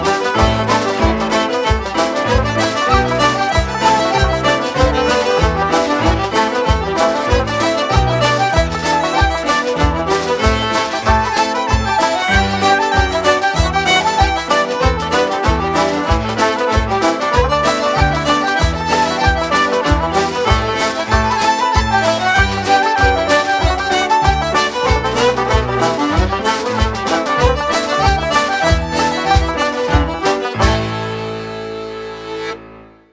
Hornpipes
accordion
flute
fiddle
banjo
piano
drums